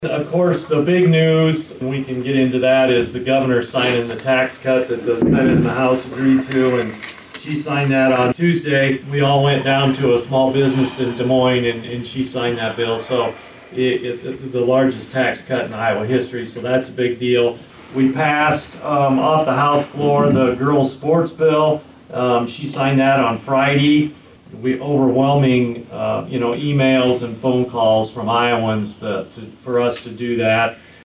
The event was held at Humboldt City Hall.
Here are the opening remarks from Representative Sexton detailing some highlights in the Iowa legislature from last week.